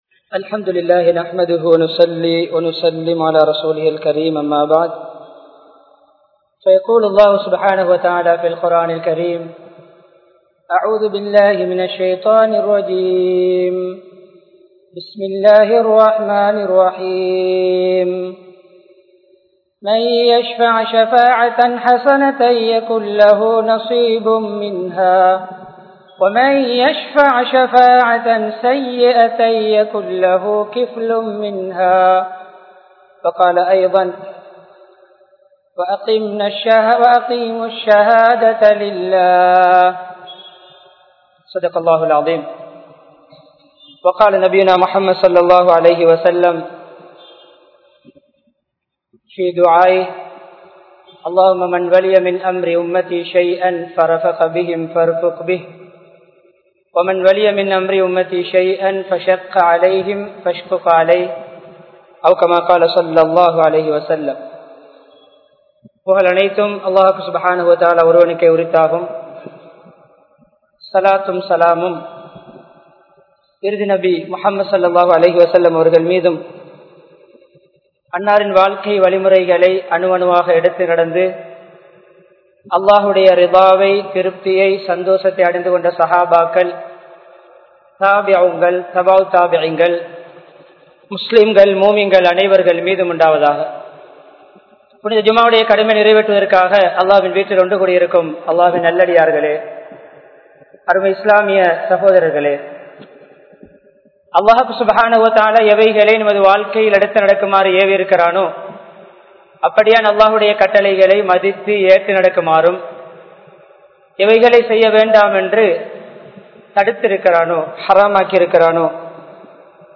Islaathin Paarvaiel Thalamaithuvam (இஸ்லாத்தின் பார்வையில் தலைமைத்துவம்) | Audio Bayans | All Ceylon Muslim Youth Community | Addalaichenai
Colombo 12, Aluthkade, Muhiyadeen Jumua Masjidh